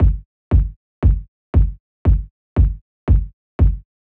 04 Kick.wav